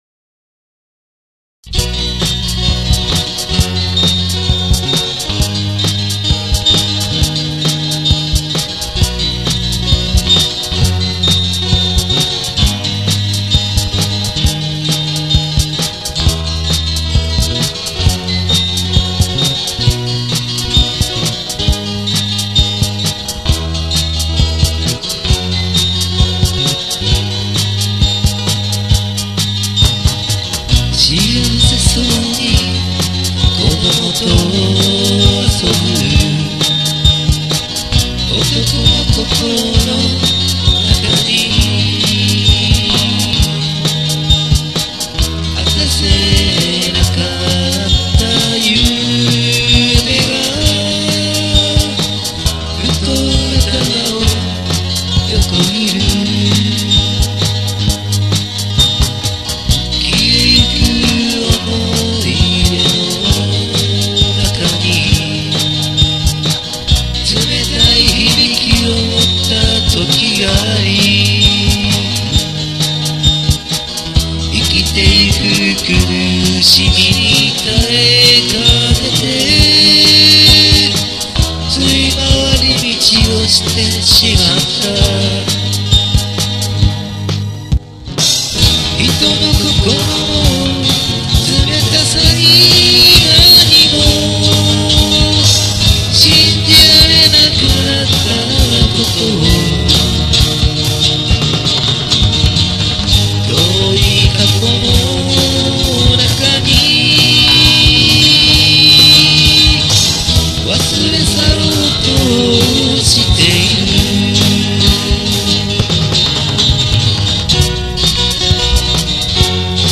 アコギ1  Gibson
アコギ2  Applause by Ovation
アコギ3  S．Yairi (NashVill)
ベース  AriaproⅡ
ドラム  PistonCollage
キーボード  PistonCollage
しっかしぃ～～～～～声が酒焼けっすねぇ(;^_^A アセアセ・・・
ただ・・・最近なまけてるたからか今一つ音がパッとしませんねぇ。
S．Yairiをナッシュビルチューニングにして弾いてんですけど
もう機械的にアルペシオするしかないっすわ(;^_^A アセアセ・・・
いつもヘッドフォンでやってますんで周りで居たら電気楽器は無音なんっすけどね。